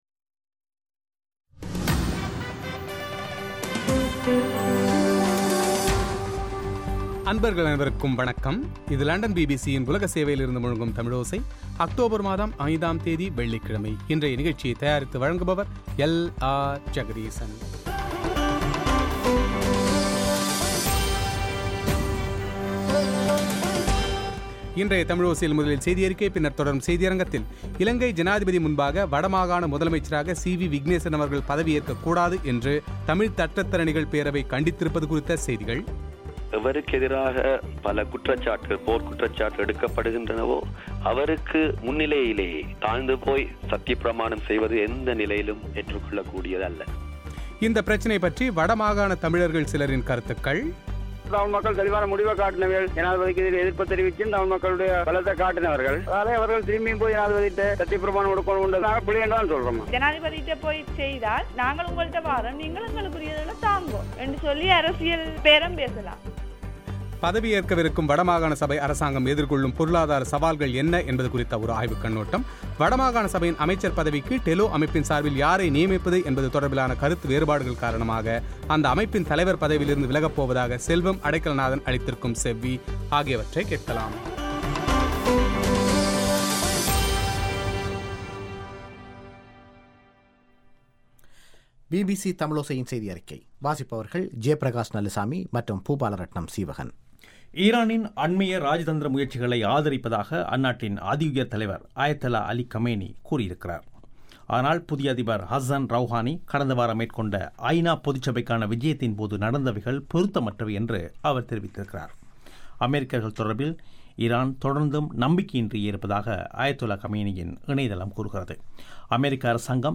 வடமாகாணசபையின் அமைச்சர் பதவிக்கு டெலோ அமைப்பின் சார்பில் யாரை நியமிப்பது என்பது தொடர்பிலான கருத்து வேறுபாடுகள் காரணமாக அந்த அமைப்பின் தலைவர் பதவியிலிருந்து விலகப்போவதாக செல்வம் அடைக்கலநாதன் அளித்த செவ்வி;